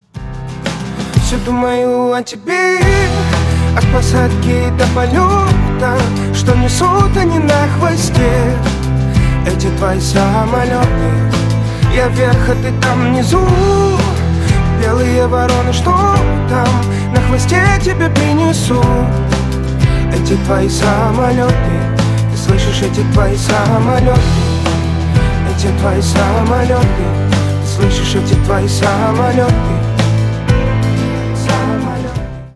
Танцевальная громкая мелодия на звонок, mp3 и m4r.
Танцевальные рингтоны